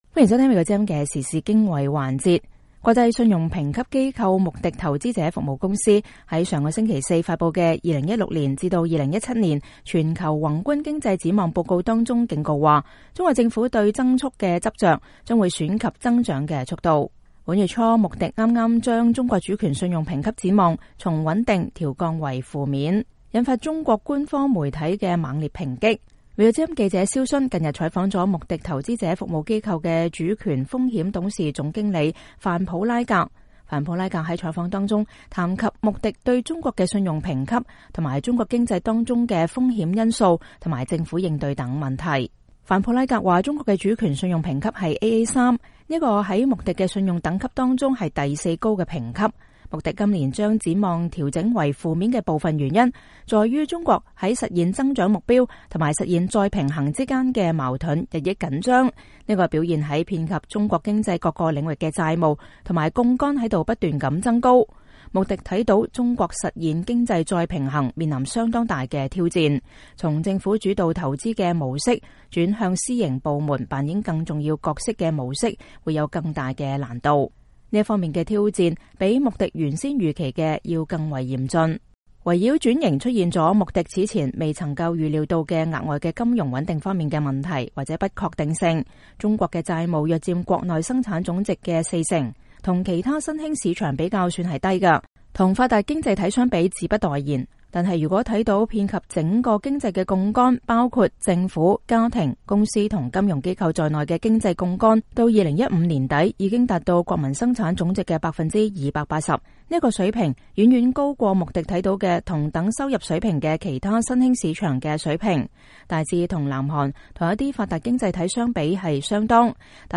專訪穆迪公司談調降中國主權信用評級展望原因